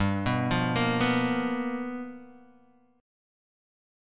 G7#9 chord